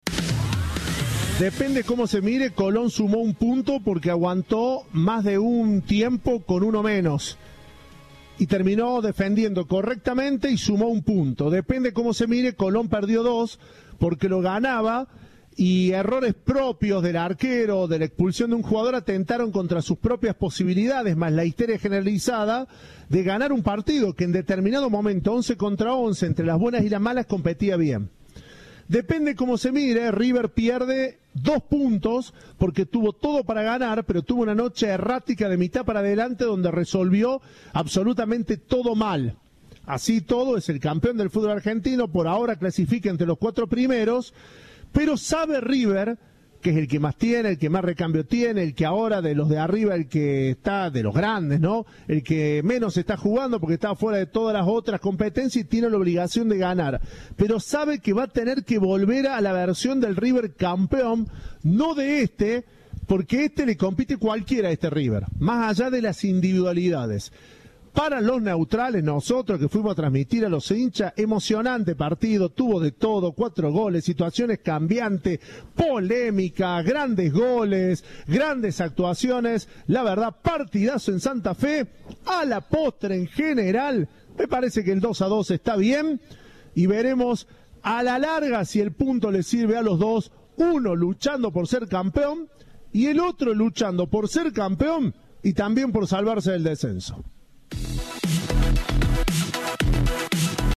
Colón, River, Comentario, Análisis, Puntos, Empate